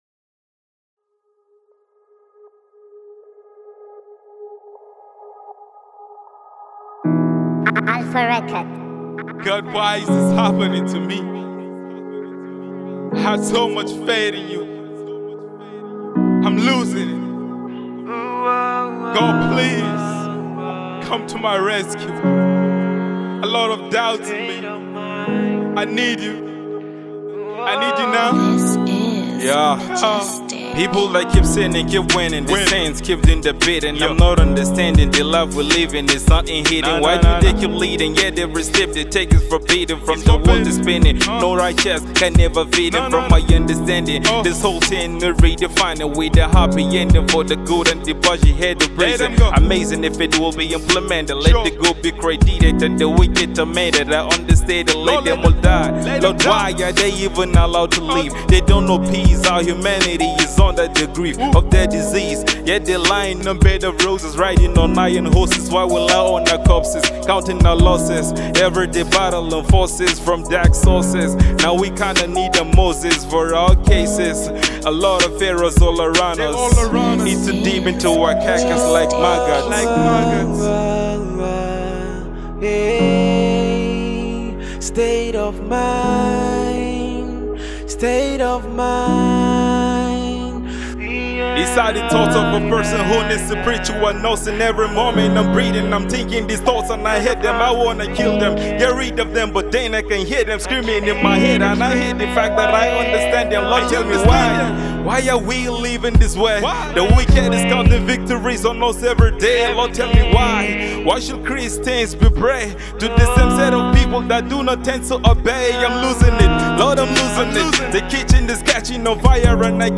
Nigerian Singer and gospel rapper